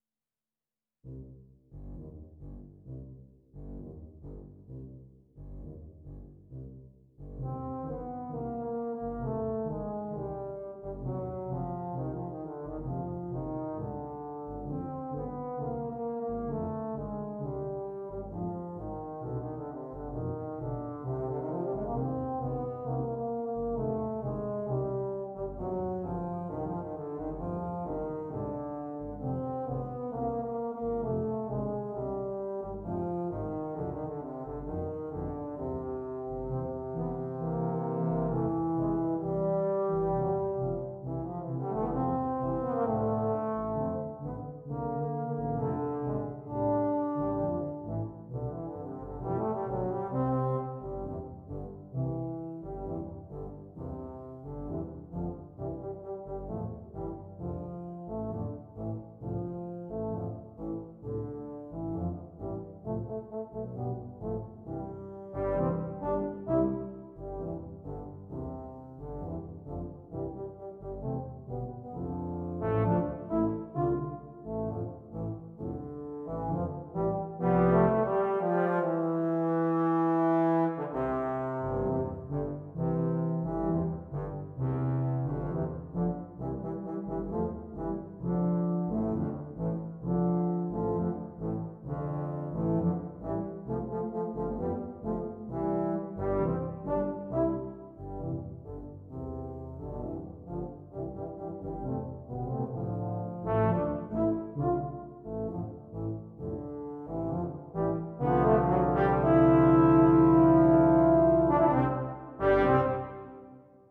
Voicing: Tuba / Euphonium Quartet